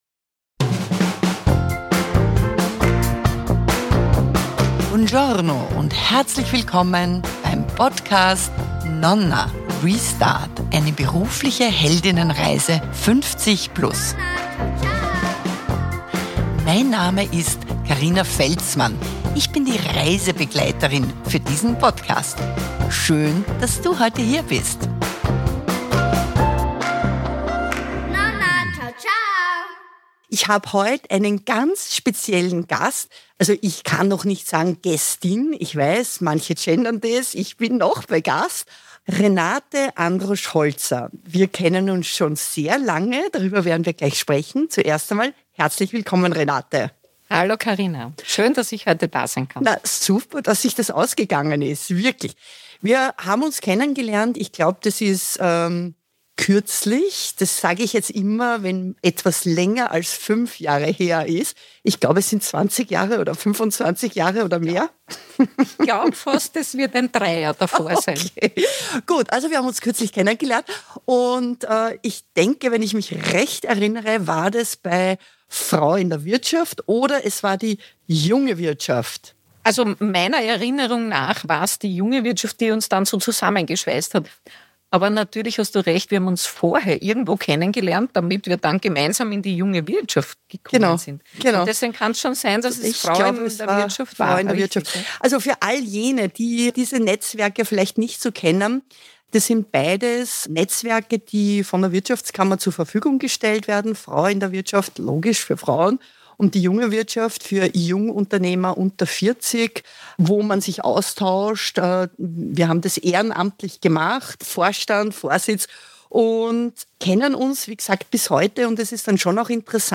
interviewt